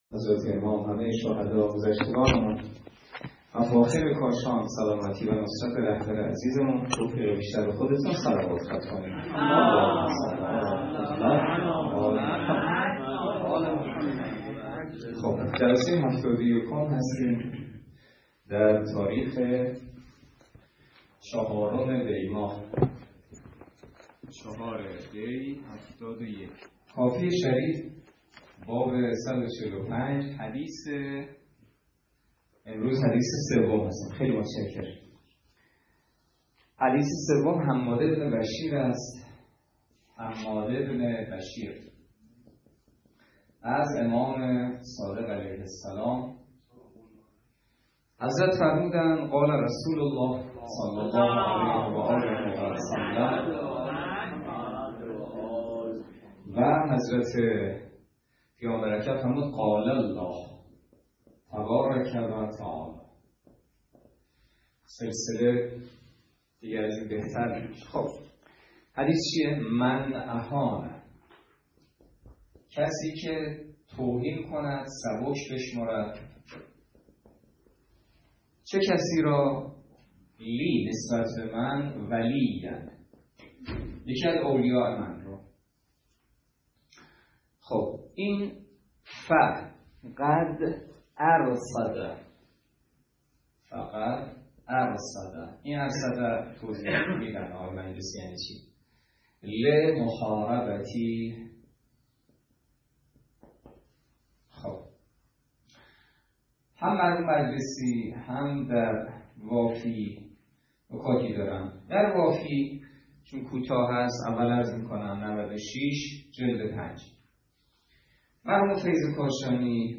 درس فقه الاجاره نماینده مقام معظم رهبری در منطقه و امام جمعه کاشان - سال سوم جلسه هفتاد و یکم